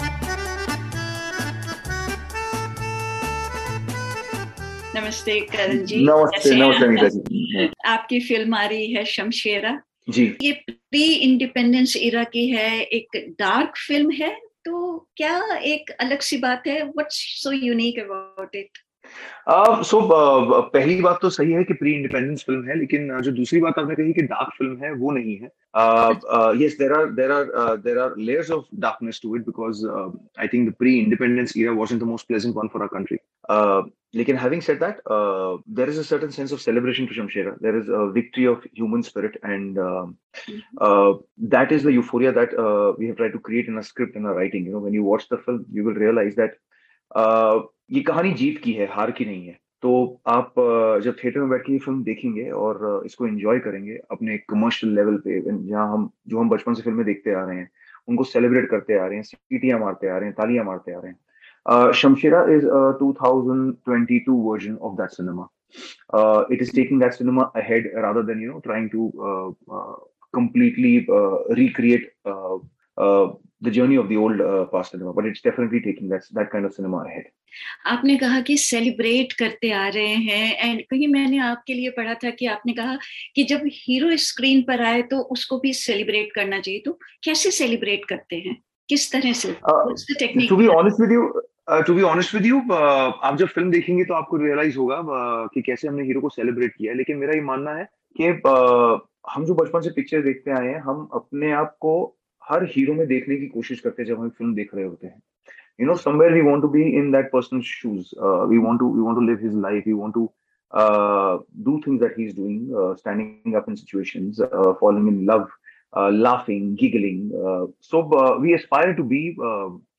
hindi_imterviewkaran_shamshera__0.mp3